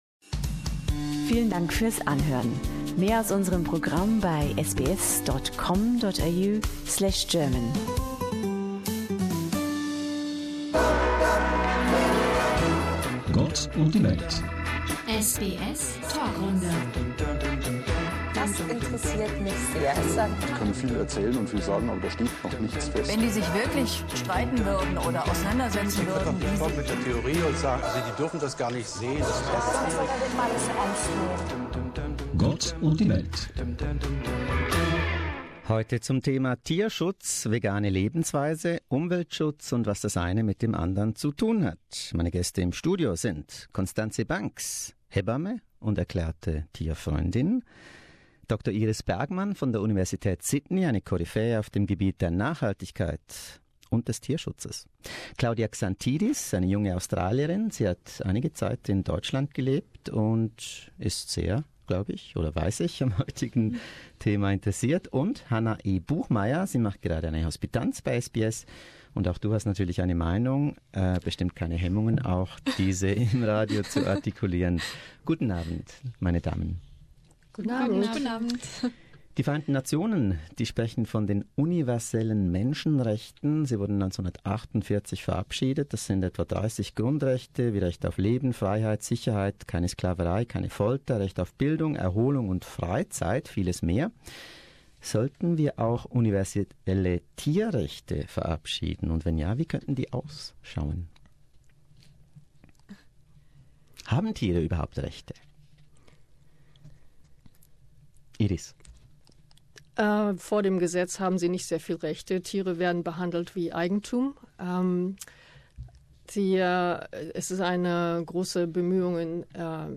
Mehr dazu, in einer lebhaften SBS Panelrunde mit vier Gästen aus Melbourne, Sydney und München.